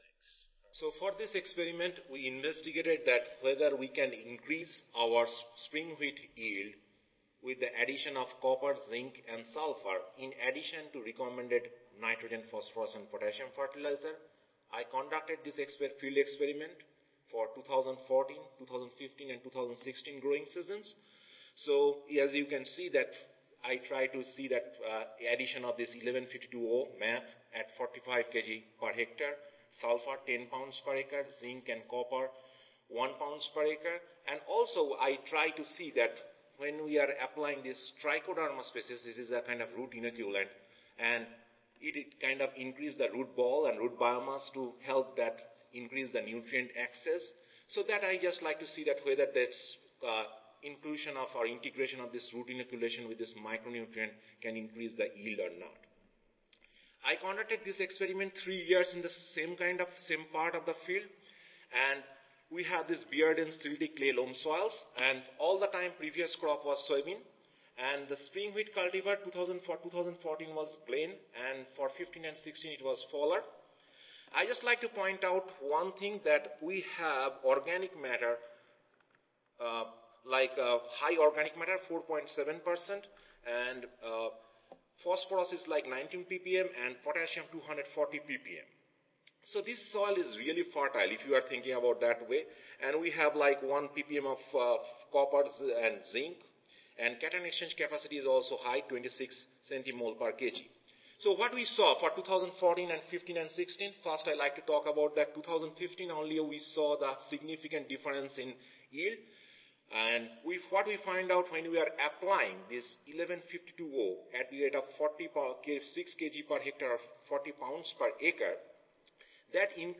Oral Session
Phoenix Convention Center North, Room 126 B
Audio File Recorded Presentation